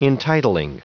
Prononciation du mot entitling en anglais (fichier audio)